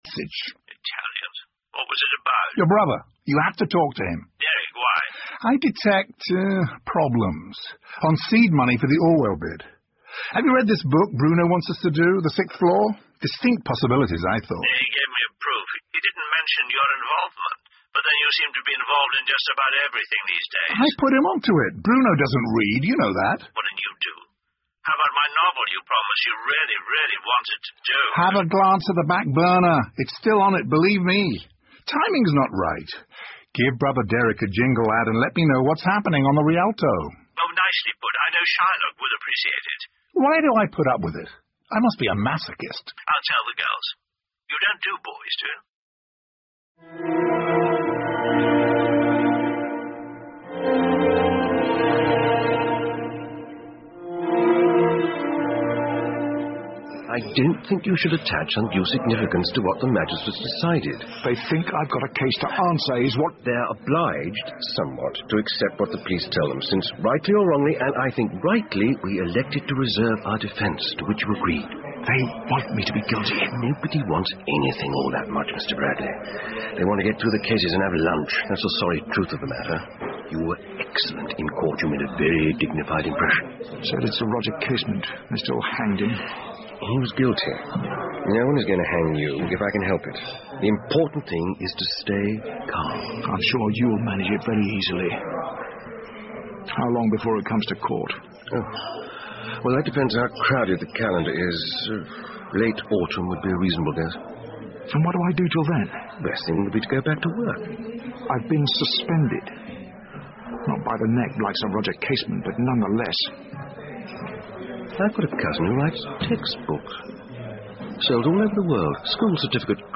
英文广播剧在线听 Fame and Fortune - 29 听力文件下载—在线英语听力室